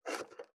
489ナイフ,調理音,まな板の上,料理,
効果音厨房/台所/レストラン/kitchen食器食材